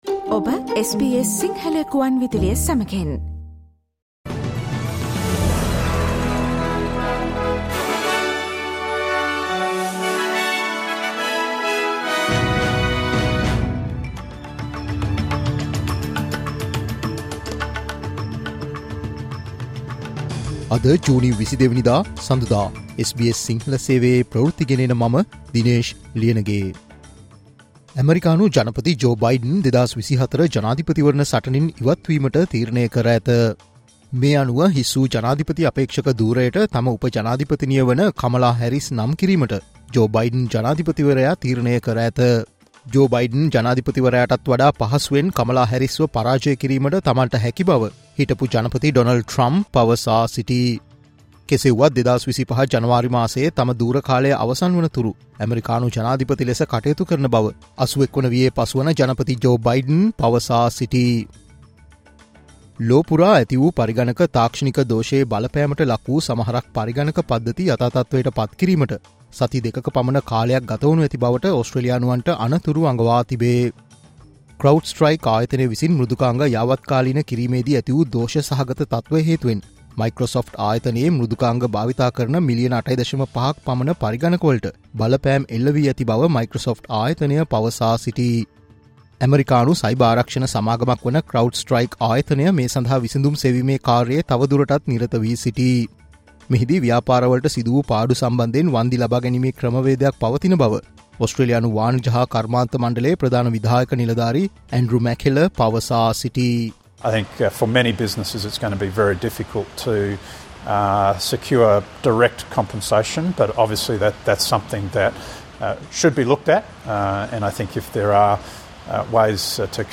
President Joe Biden has announced his withdrawal from the 2024 Presidential election: News Flash July 22
Listen to SBS Sinhala News Flash today.